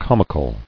[com·i·cal]